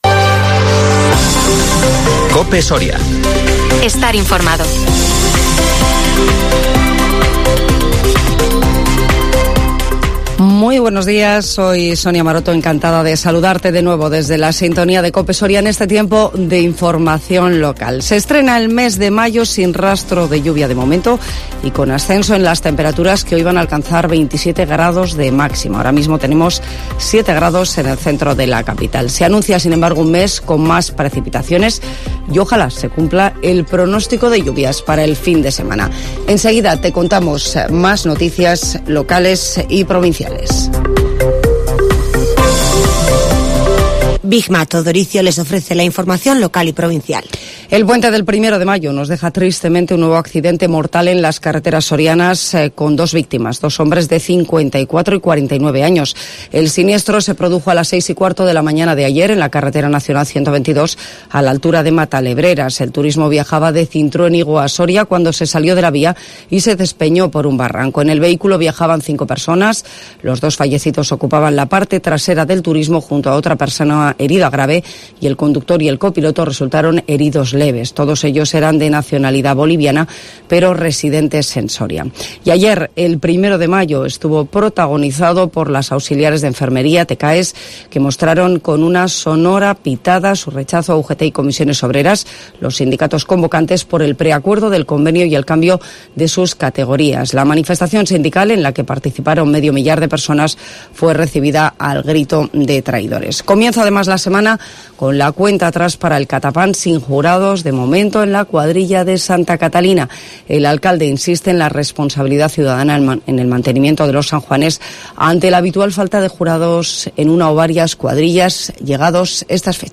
INFORMATIVO MATINAL COPE SORIA 2 MAYO